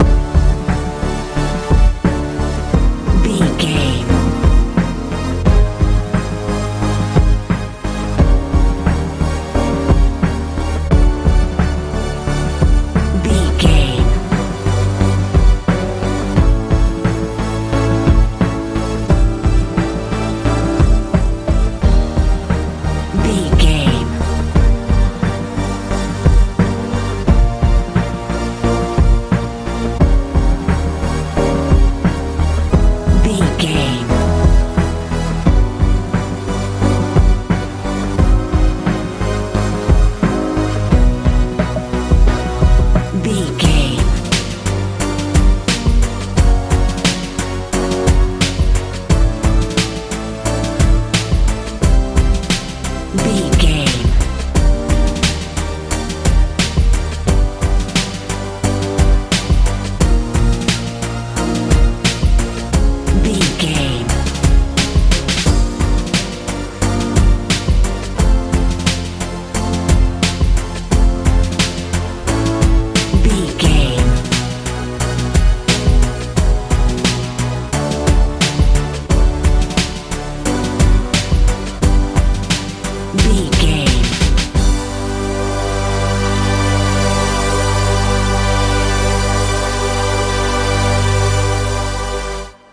modern pop feel
Ionian/Major
D
dramatic
heavy
synthesiser
bass guitar
drums
80s
90s